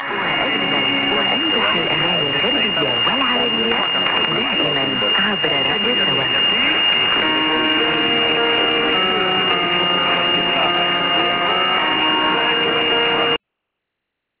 Radio Sawa station identification ).